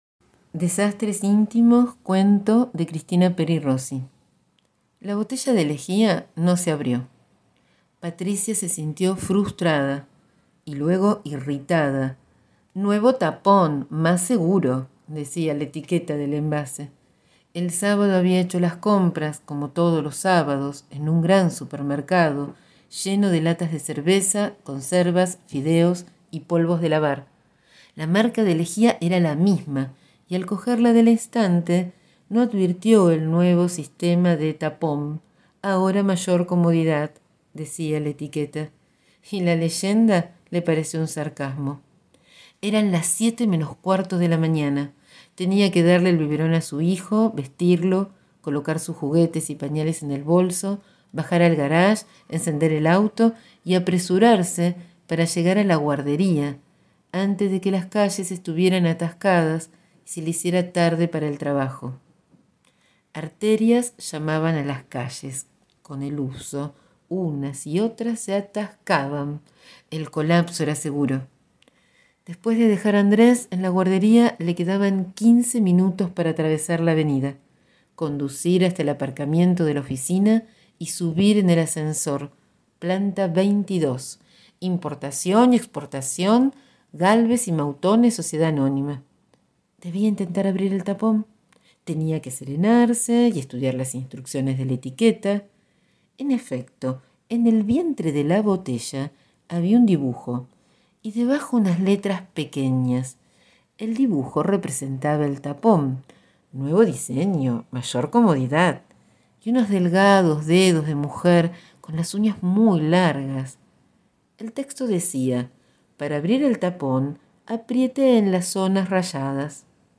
Hoy leo «Desastres íntimos» cuento de la gran poeta y escritora Cristina Peri Rossi (Uruguay 1941).